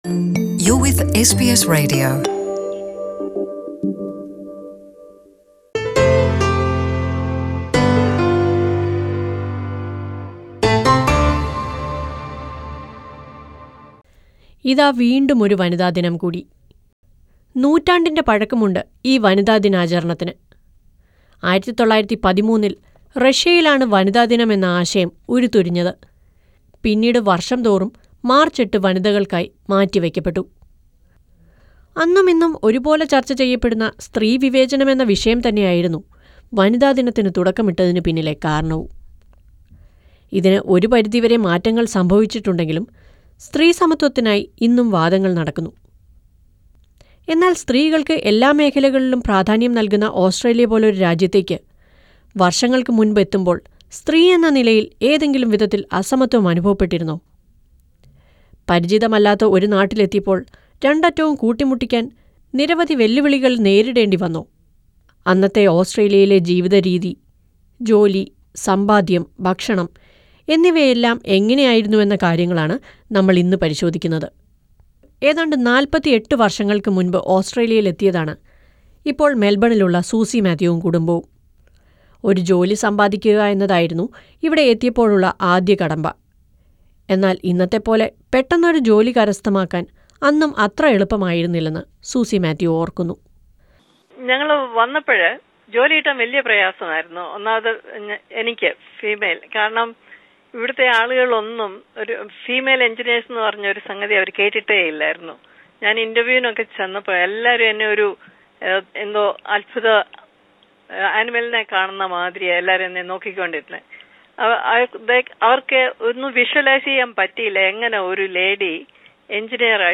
Listen to a report on two women who arrived in Australia 40 years back sharing their experience on how Australia treated them decades ago.